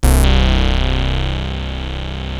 OSCAR C2 2.wav